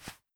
Jump 1.wav